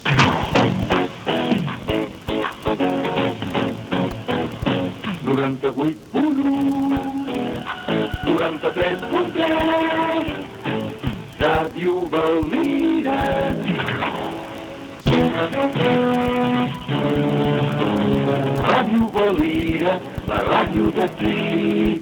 Indicatius de l'emissora